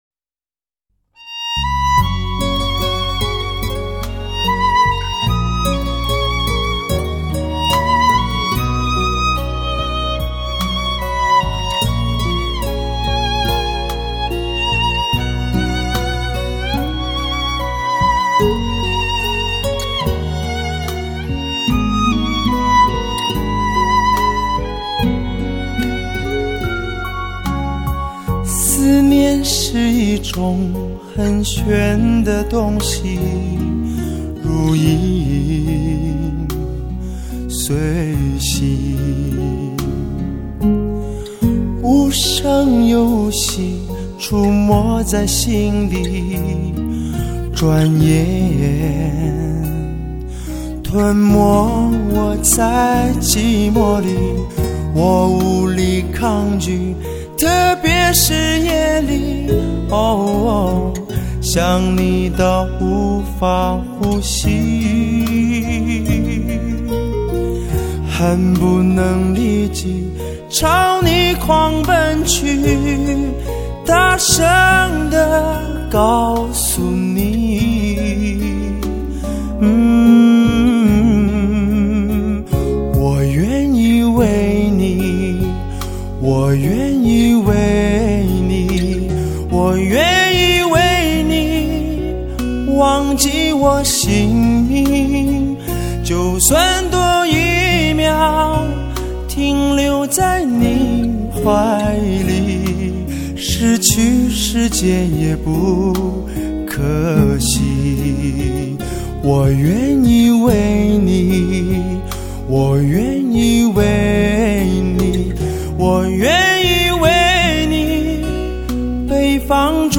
极品高保真唱片
清澈而富有磁性的声音，
再加上娓婉的演唱技巧，